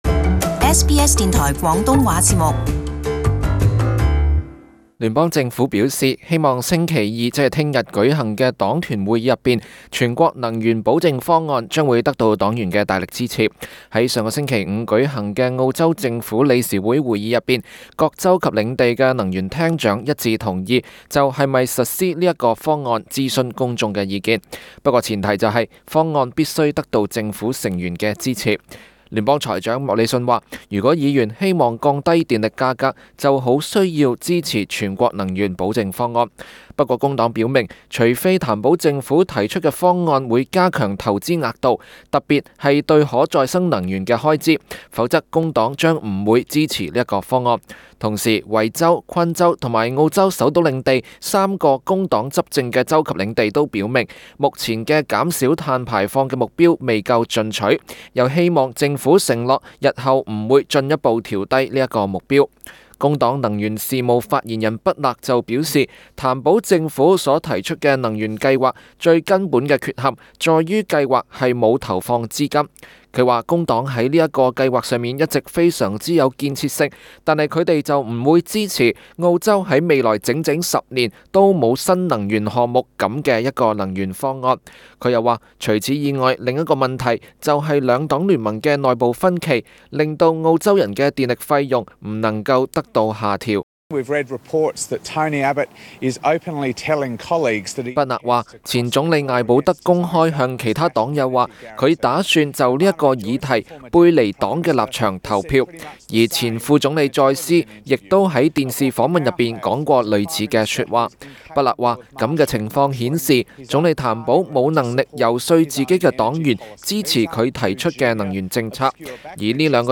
【時事報導】朝野續就能源政策互批